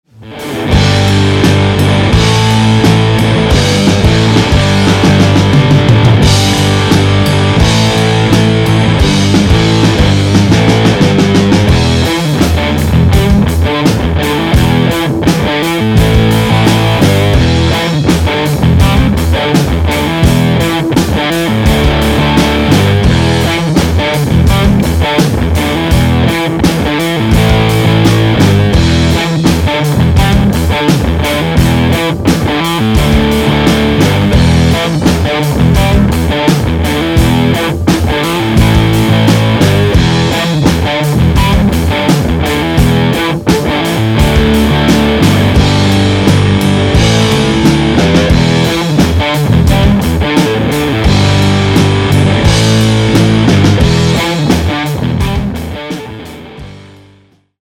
I think you'll be blown away by how big it sounds in the context of a band!!!
The rhythm section was recorded full out balls to the wall!! 2 watts!!
Dirty politician - big muff into TriFly into 212 Celestion Classic Lead 80's